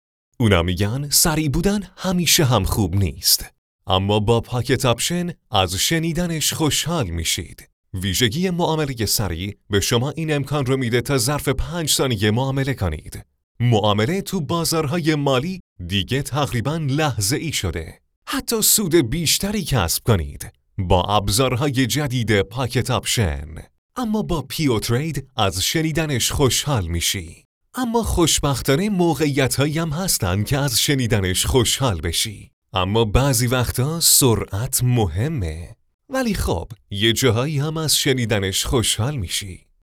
Male
Young
Adult